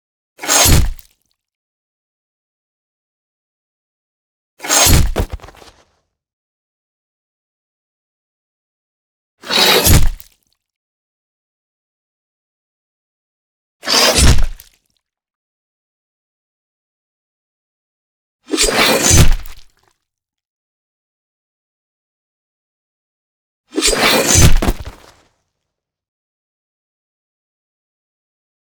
weapon
Guillotine Blade Drops